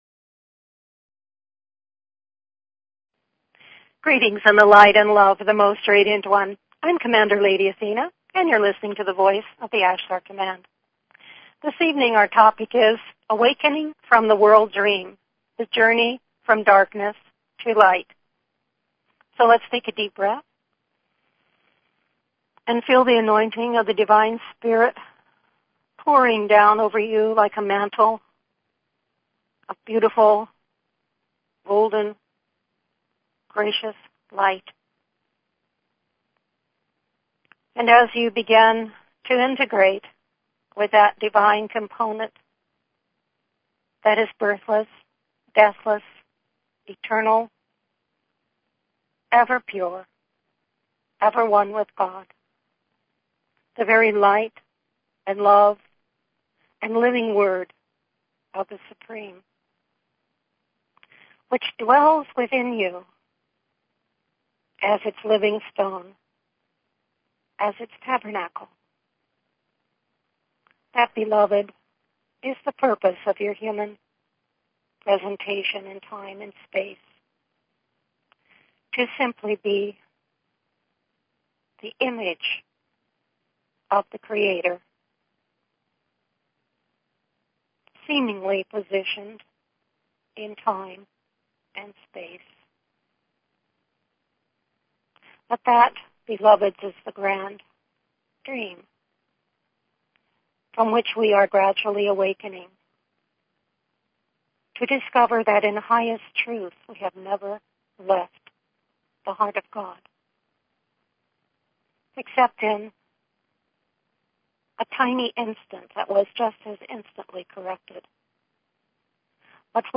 The Voice of the Ashtar Command Please consider subscribing to this talk show.